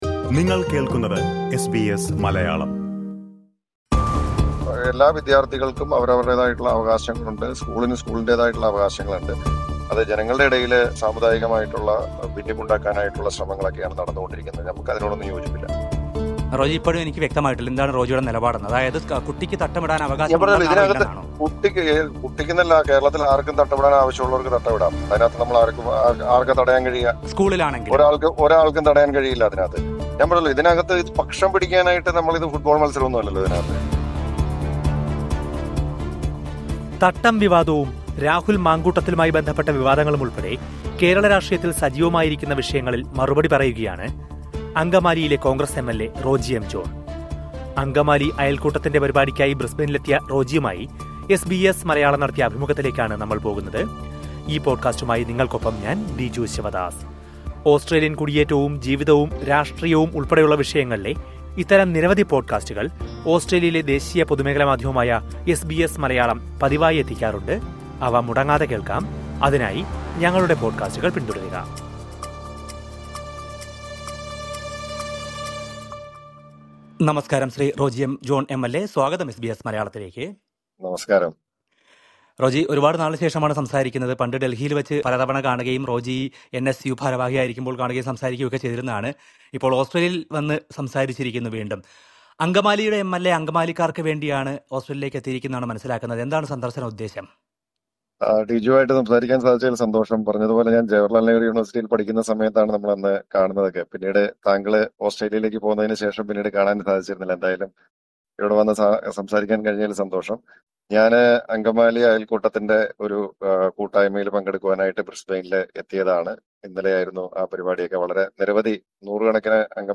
അങ്കമാലി അയൽക്കൂട്ടത്തിന്റെ പരിപാടിയിൽ പങ്കെടുക്കാനായി ബ്രിസ്ബൈനിലേക്കെത്തിയ കോൺഗ്രസ് MLA റോജി എം ജോൺ SBS മലയാളത്തിൻറെ ചോദ്യങ്ങൾക്ക് മറുപടി പറഞ്ഞു. തട്ടം വിവാദവും, രാഹുൽ മാങ്കൂട്ടത്തിലിനെതിരെയുള്ള ആരോപണങ്ങളും ഉൾപ്പെടെയുള്ള വിഷയങ്ങളിൽ റോജി എം ജോൺ പ്രതികരിക്കുന്നത് കേൾക്കാം, മുകളിലെ പ്ലേയറിൽ നിന്ന്...